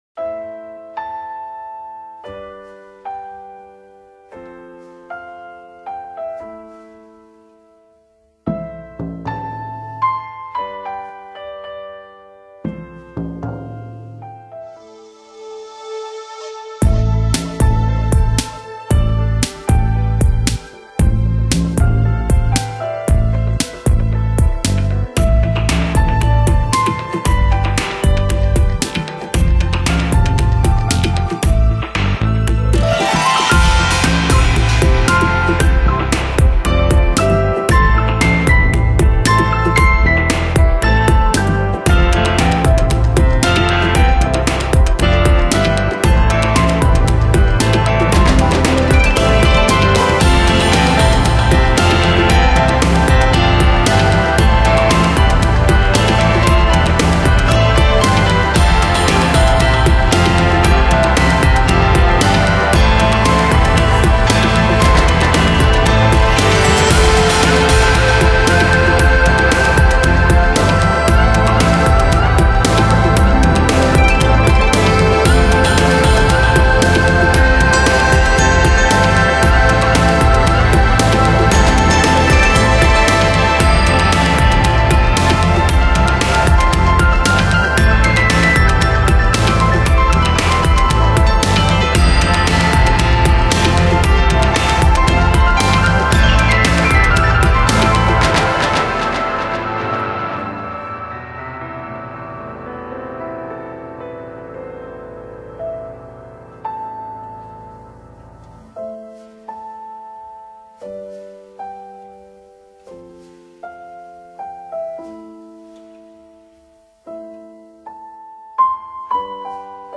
Genre: Trance, Downtempo, Ambient